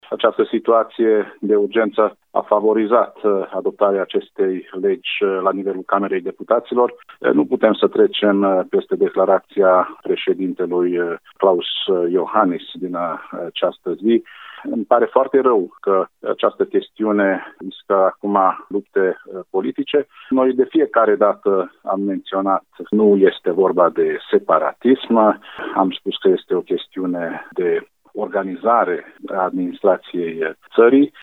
Unul dintre inițiatorii proiectului de lege, deputatul UDMR, Biro Zsolt: